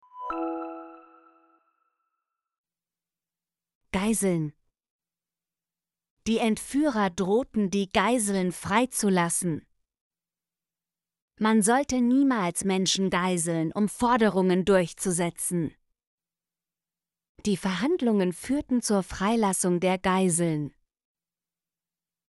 geiseln - Example Sentences & Pronunciation, German Frequency List